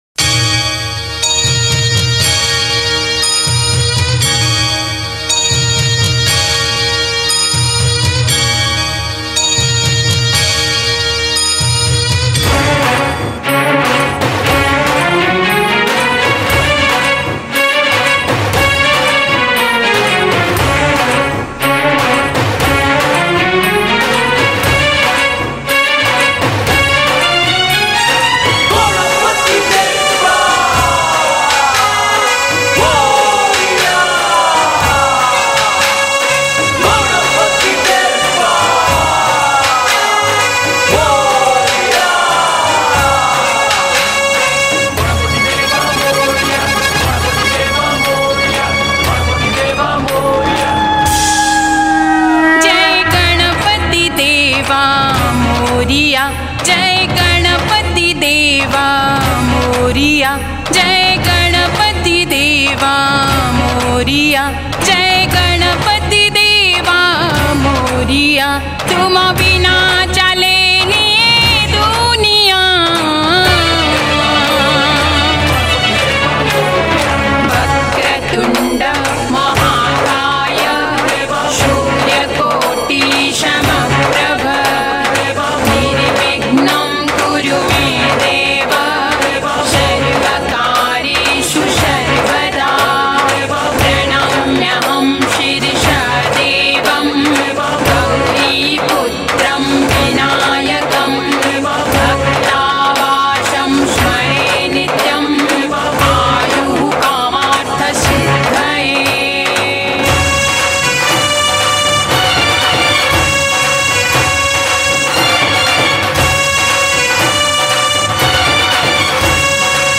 Odia Bhajan